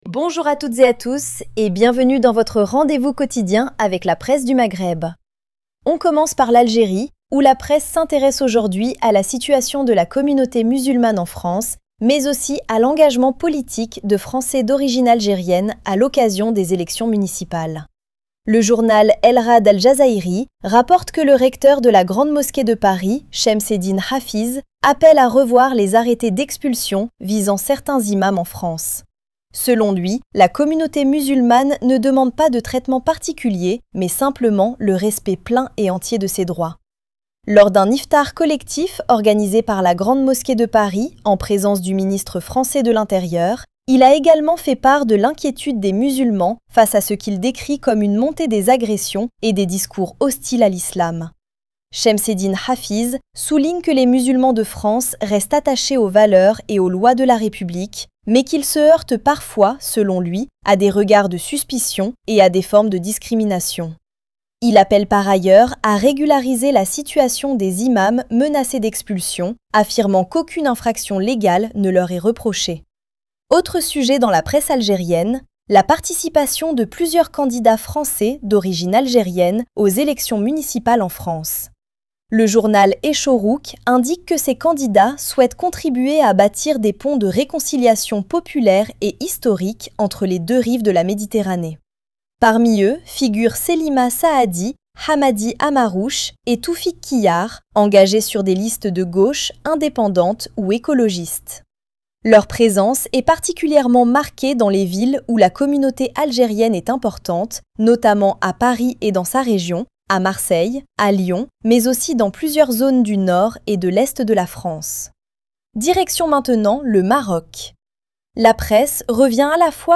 Revue de presse des médias du Maghreb